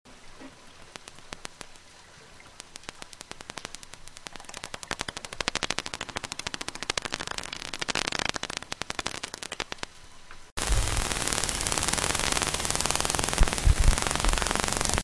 Cliquez sur les spectrogrammes pour écouter les enregistrements sonores sous-marins de grands dauphins collectés sur la côte ouest du Cotentin :
localise et distingue ses proies grâce à un système d’émission-réception d’un faisceau d’ondes sonores à haute fréquence appelées « clics ».
clics_livre.mp3